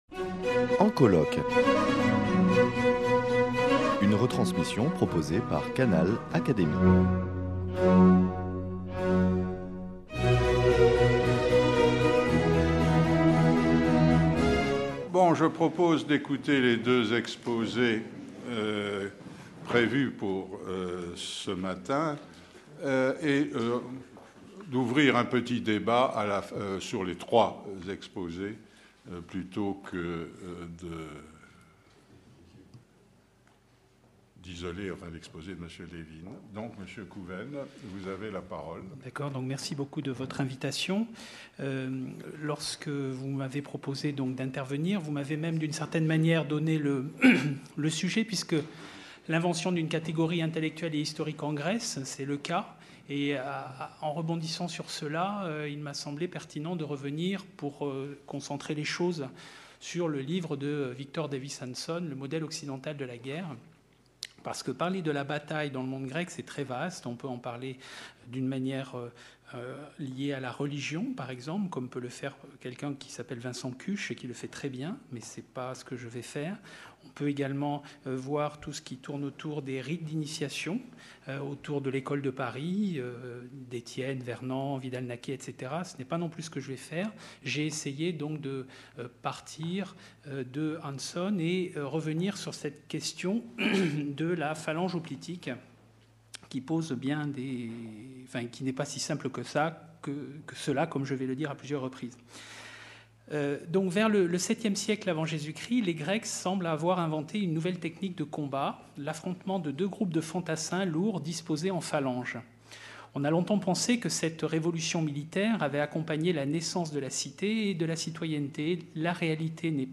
Retransmission du colloque international « La guerre et le droit » - Partie 2